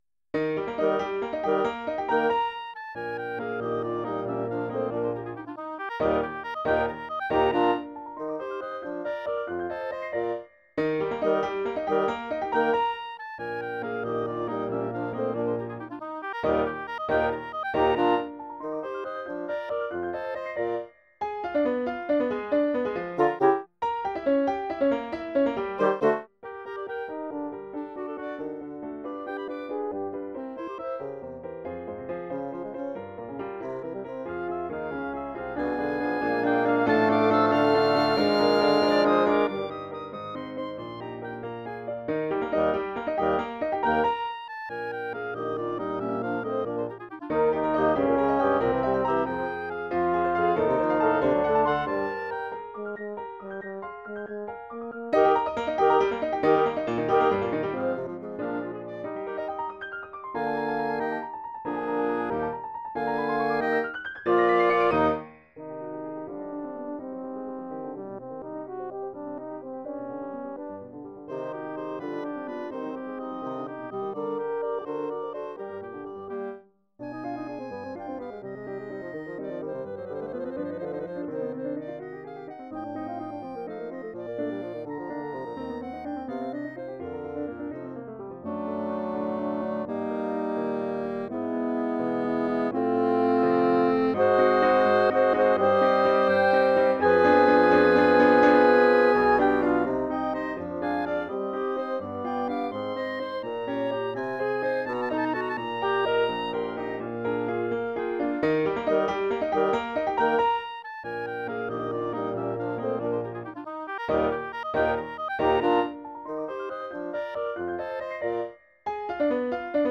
We apologize for the quality
have had to resort to midi files
For Flute, Clarinet, Horn, Bassoon and Piano